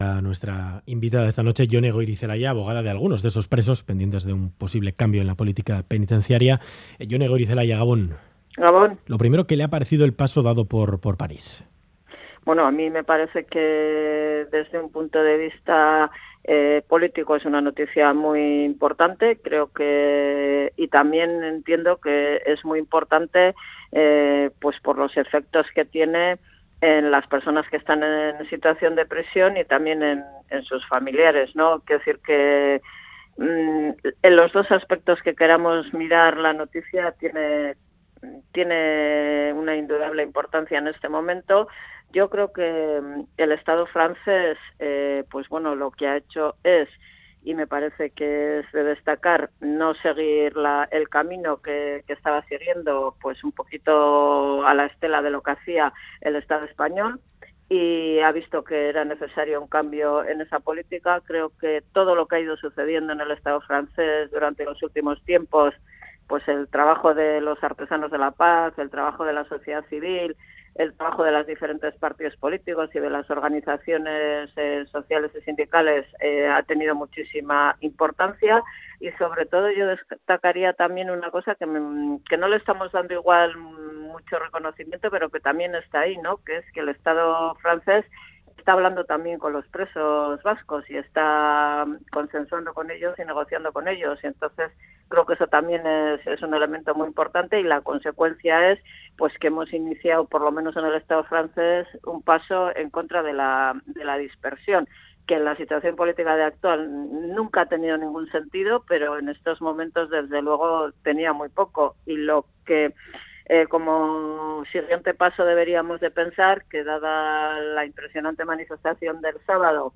Audio: Entrevista a Jone Goirizelaia, parlamentaria de EH Bildu, tras conocerse que el Estado francés comenzará a acercar en las próximas semanas a presos vascos a cárceles cercanas a Euskal Herria.